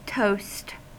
Ääntäminen
US : IPA : [ˈtoʊst]